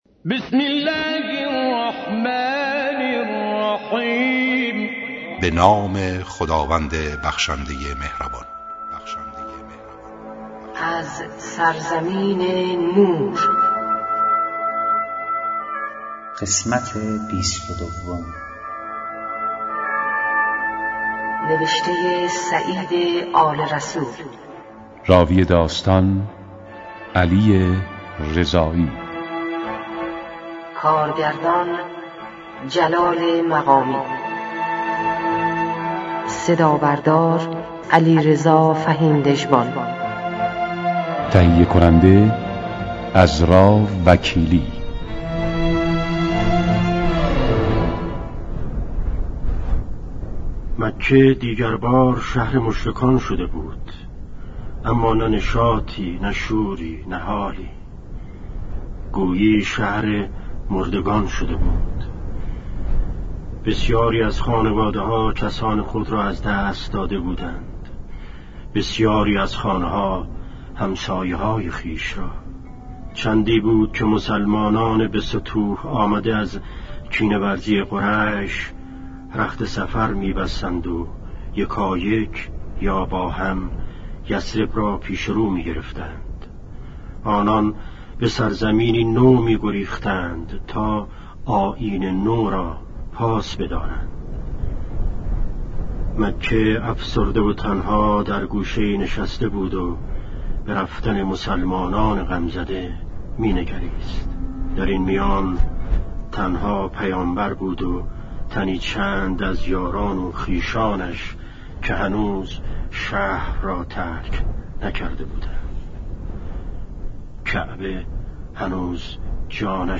داستان شنیدنی زندگی پیامبر اسلام (ص) و امام علی (ع)؛ با اجرای مشهورترین صداپیشگان، با حذف اضافات و صداگذاری جدید
کتاب گویا